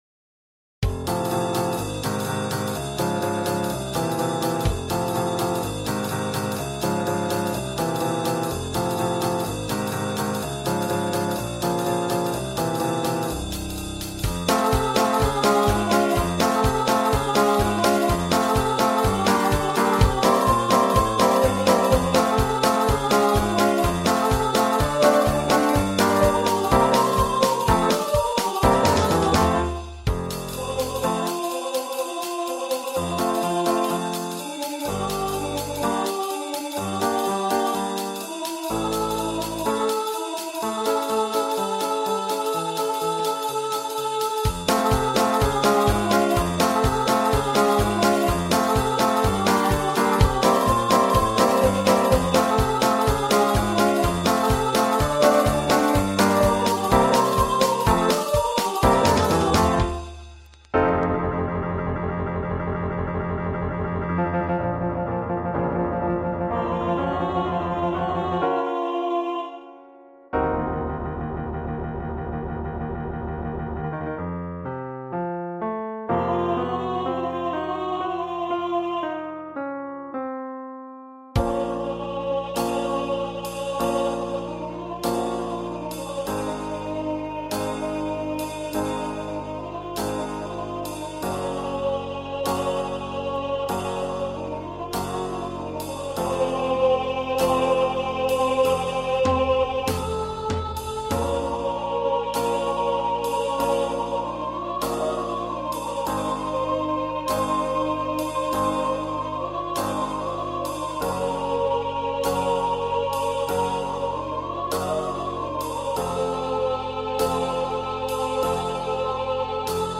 més lent
Més-lent-3-coqueta.mp3